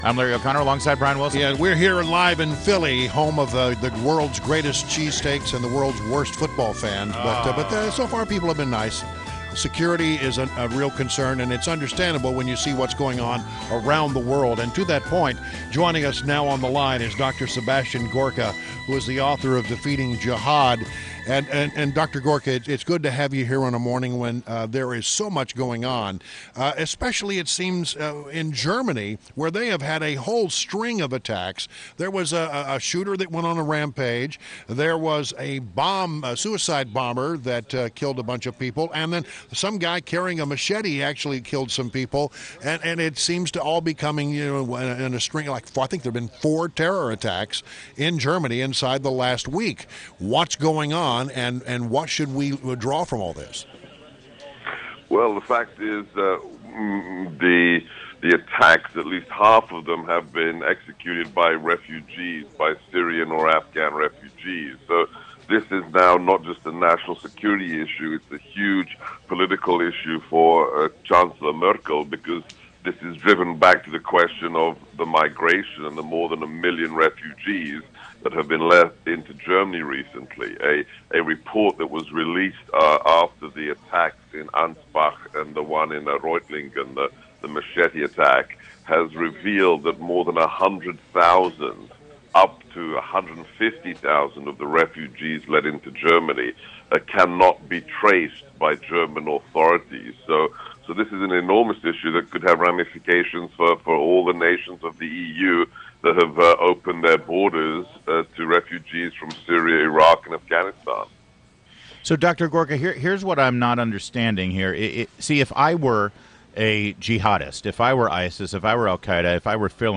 WMAL Interview - Dr. Sebastian Gorka - 07.25.16